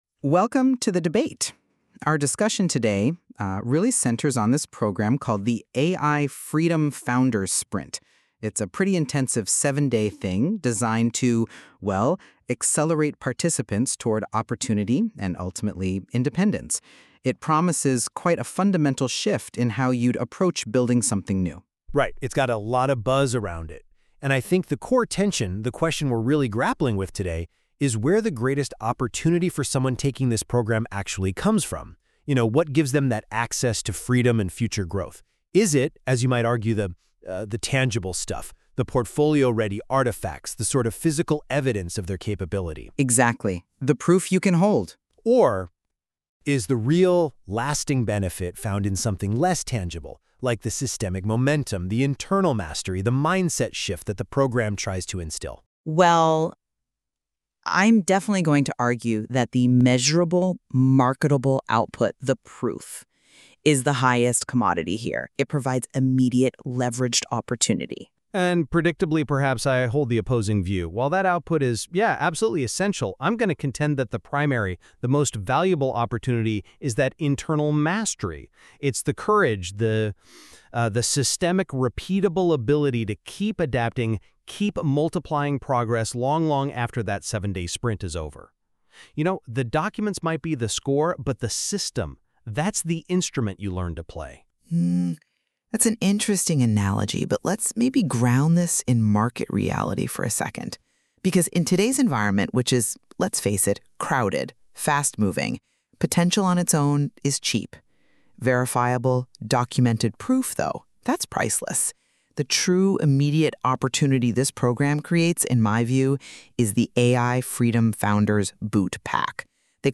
AI-Generated DEBATE Listen Now